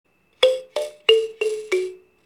アフリカ ひょうたんカリンバ (b020-13)
金属より優しい音が特徴です。
指で鉄の爪をはじいて音を出します。
この楽器のサンプル音